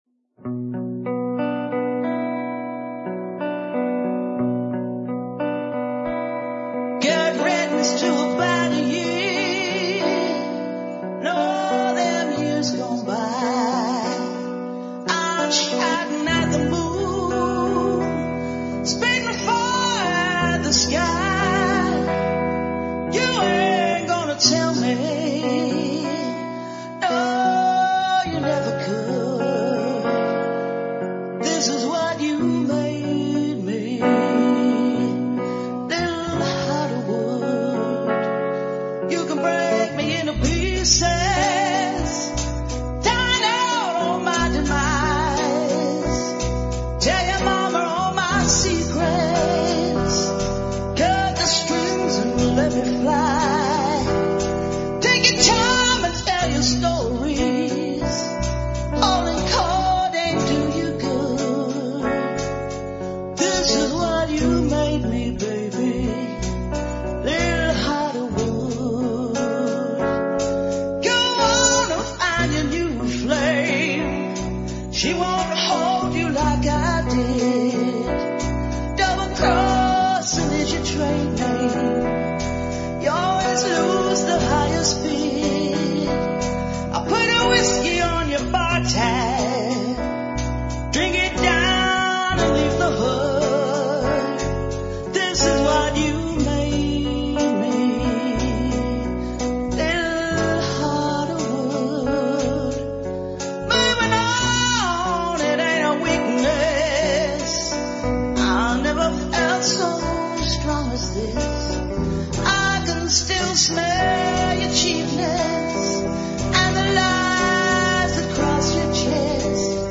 Blues Jazz Para Ouvir: Clik na Musica.